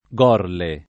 [ g0 rle ]